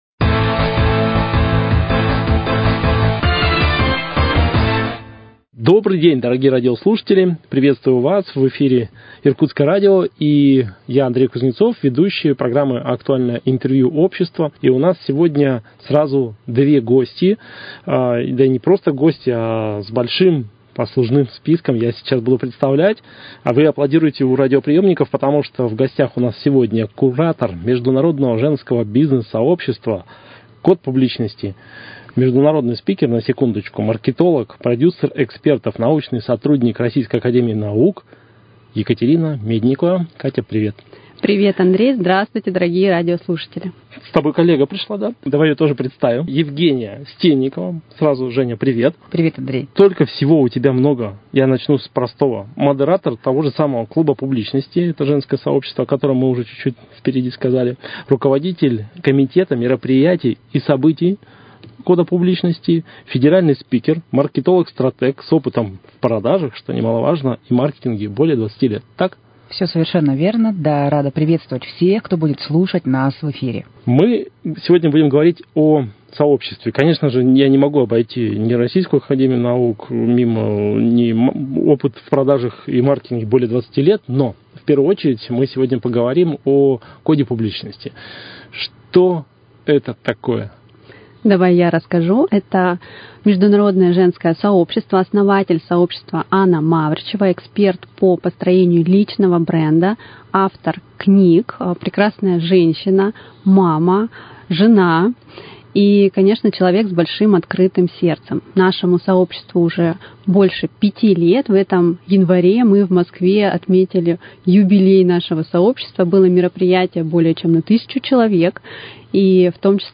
Актуальное интервью: Беседа с членами международного женского бизнес - сообщества «Клуб публичности»